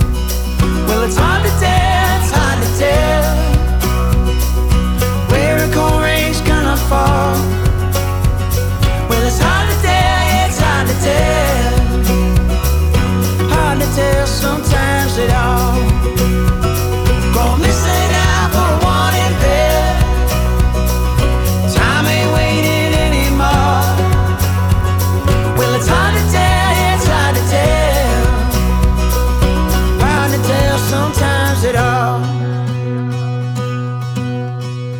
Жанр: Рок / Альтернатива / Фолк-рок